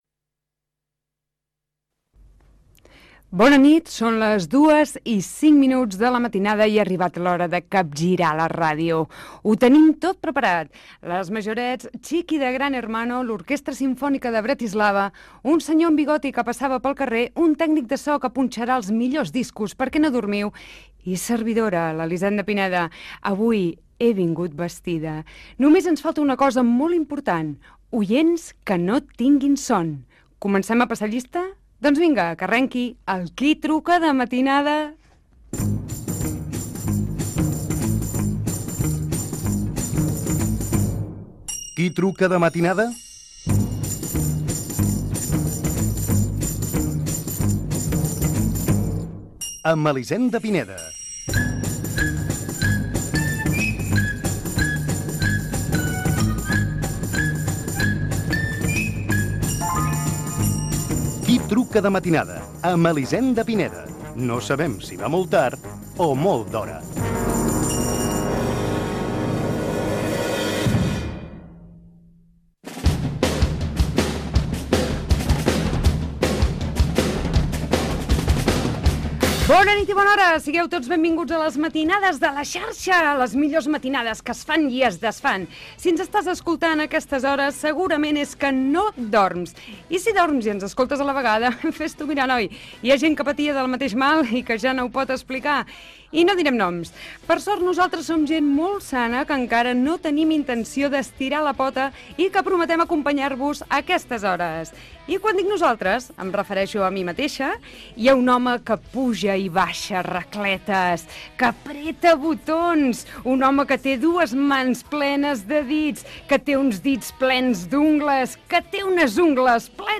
Inici del primer programa, careta, comentari sobre el tècnic de so, repàs a la premsa del dia i converses amb els oients Gènere radiofònic Participació